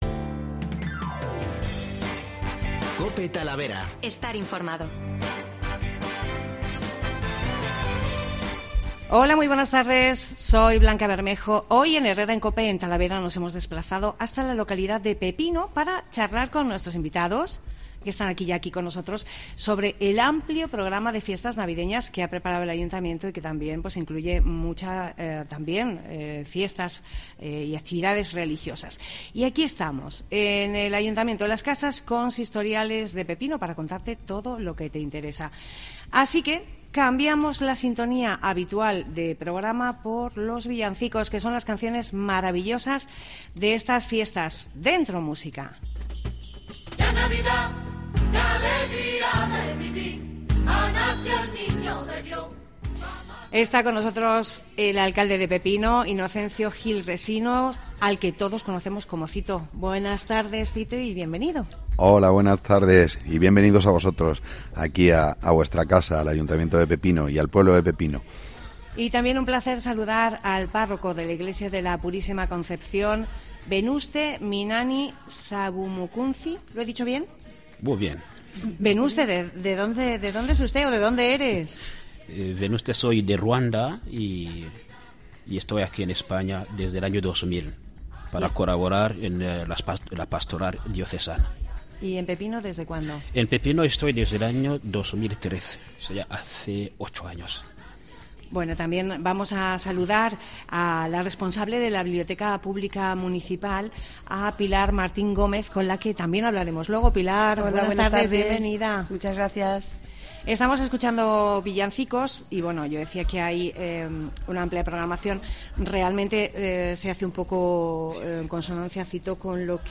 Herrera en COPE Talavera realiza el programa desde la localidad de Pepino para conocer como van a celebrar la Navidad, los proyectos futuros.